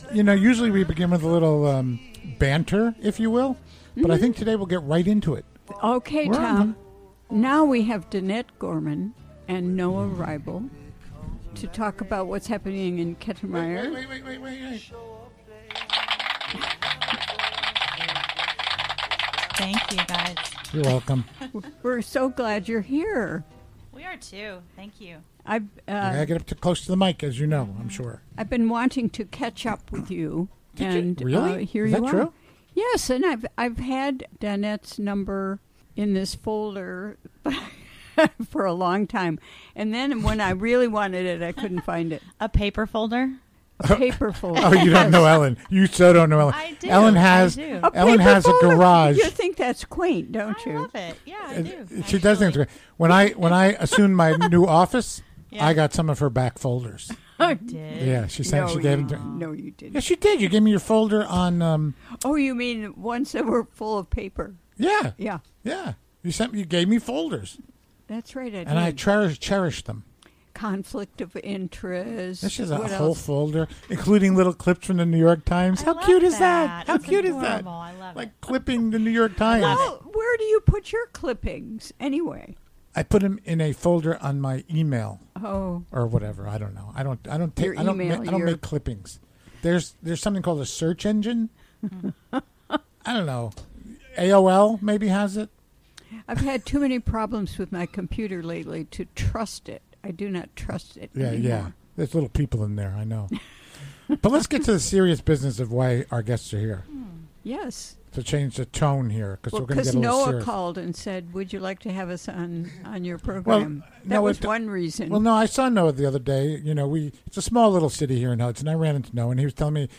Recorded during the WGXC Afternoon Show of Thursday, February 1, 2018.